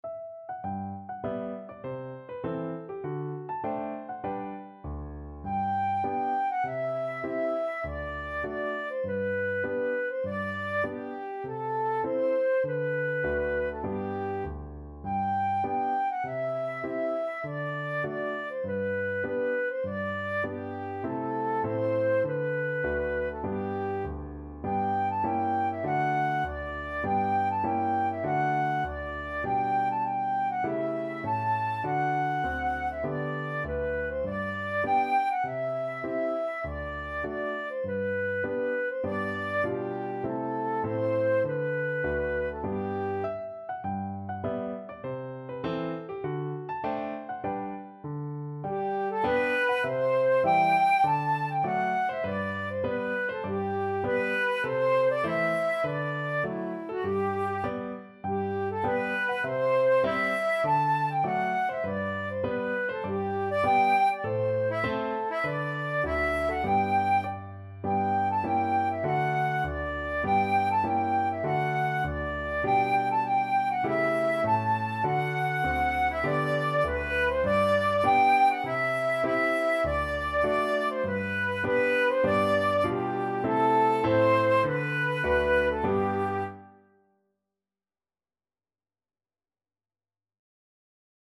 Flute
G major (Sounding Pitch) (View more G major Music for Flute )
Moderato
4/4 (View more 4/4 Music)
Traditional (View more Traditional Flute Music)